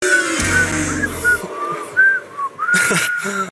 Sound Buttons: Sound Buttons View : The Verry Polish Tv Weird Sound